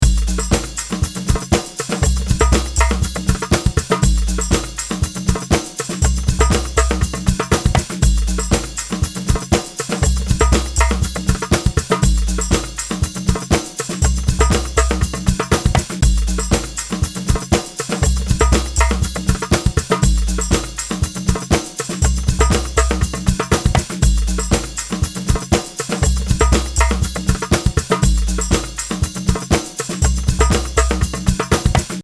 Akustik Loops
eigenharp_pico_testbericht__akustik_lopps.mp3